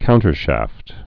(kountər-shăft)